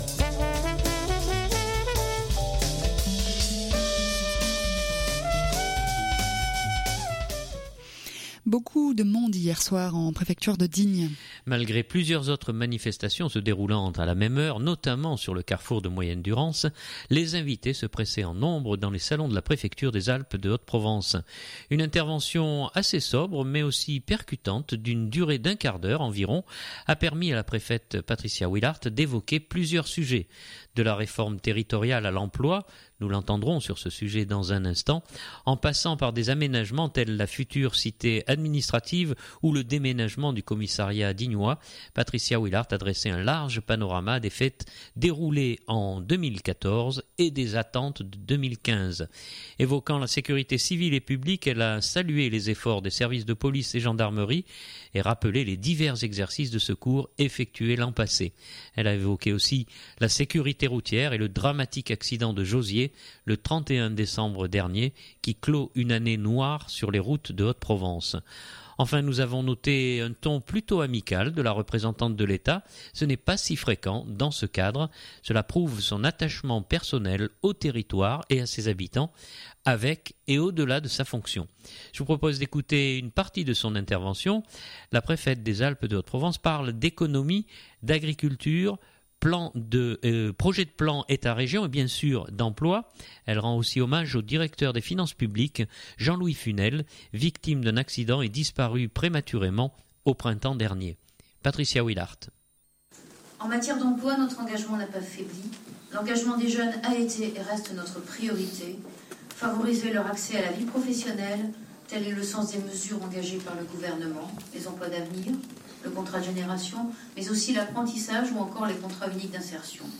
Beaucoup de monde hier soir en Préfecture de Digne.
Enfin, nous avons noté un ton plutôt amical de la représentante de l’Etat, ce n’est pas si fréquent dans ce cadre.
Je vous propose d’écouter une partie de son intervention. La Préfète parle d’économie, agriculture, projet de plan Etat-Région et bien sûr d’emploi.